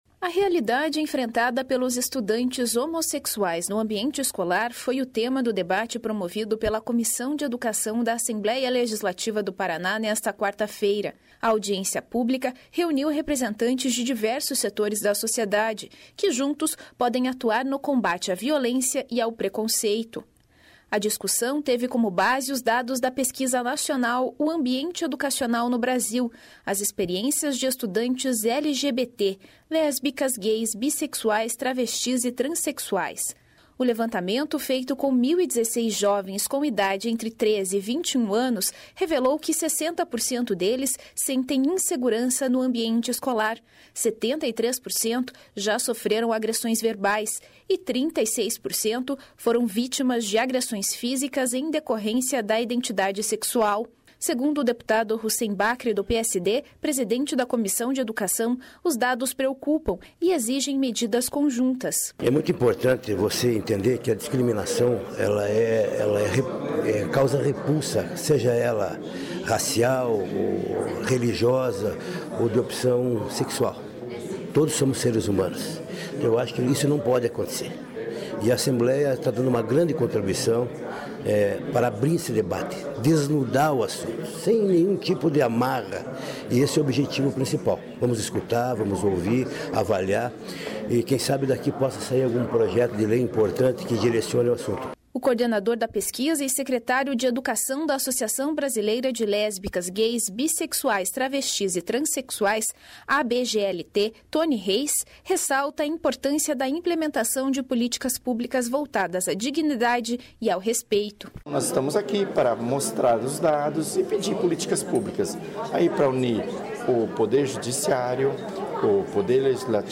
((Descrição do áudio))A realidade enfrentada pelos estudantes homossexuais no ambiente escolar foi o tema do debate promovido pela Comissão de Educação da Assembleia Legislativa do Paraná nesta quarta-feira (10). A audiência pública reuniu representantes de diversos setores da sociedade, que juntos...